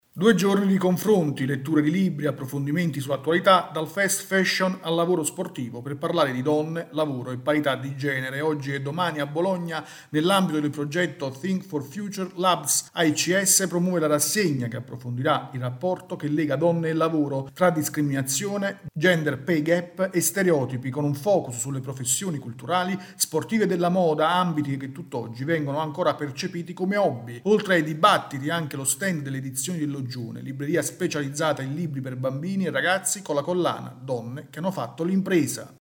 Cita Sciascia la 13esima edizione di Trame, il festival dei libri sulle mafie, da domani al 23 giugno a Lamezia Terme. Il servizio